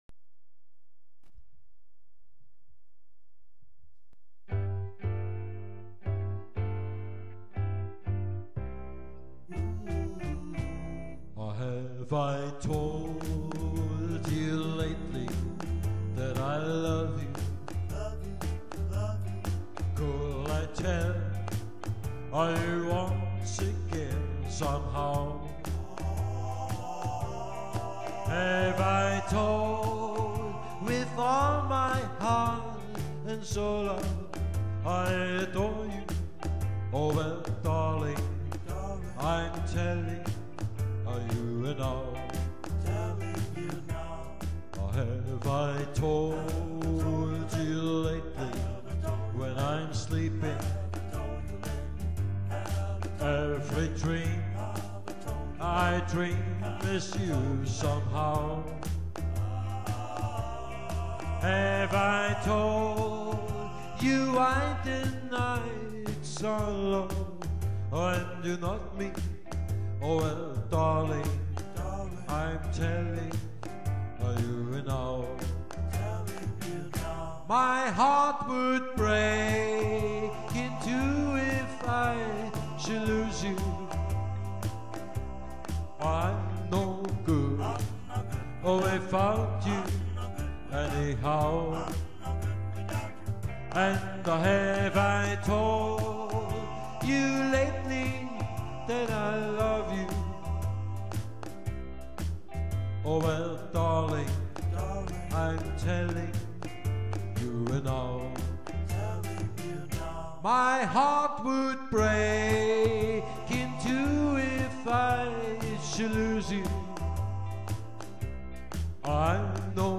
Rock`n`Roll wie in den 1950er- und frühen 1960er- Jahren.